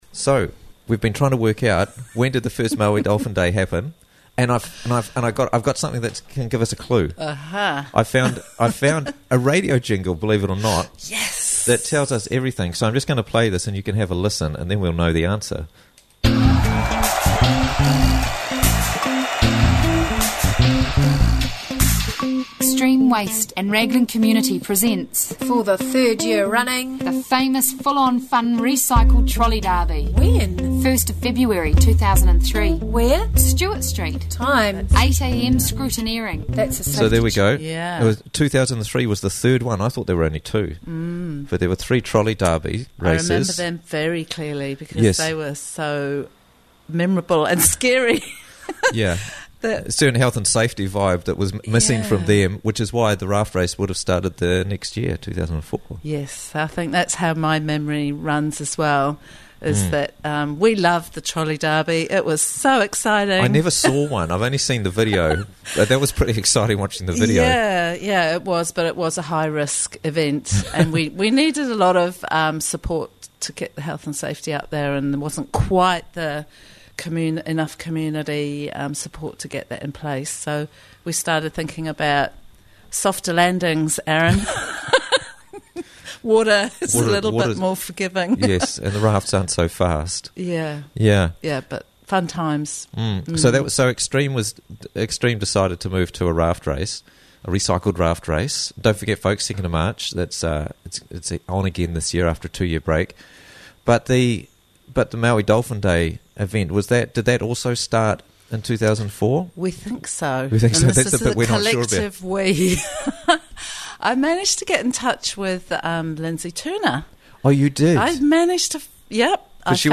History of Maui Dolphin Day - Interviews from the Raglan Morning Show